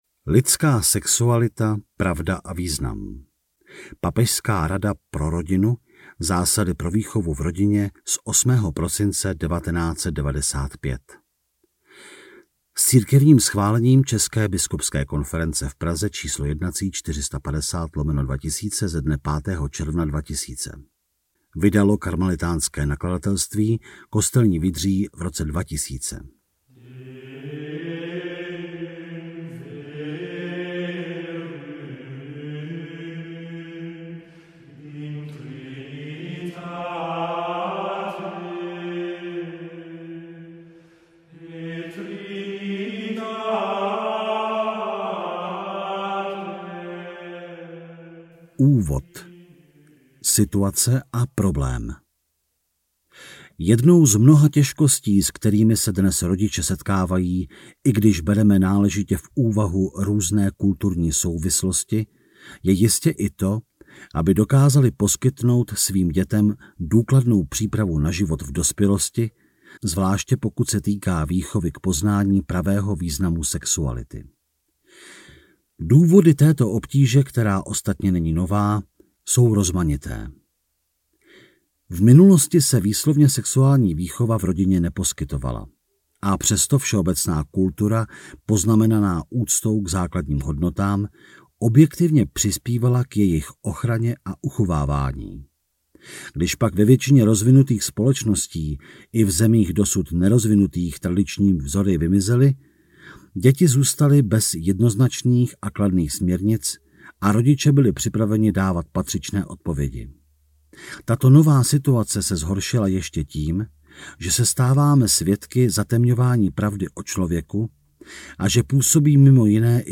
LIDSKÁ SEXUALITA | Studio Vox - křesťanské mluvené slovo zdarma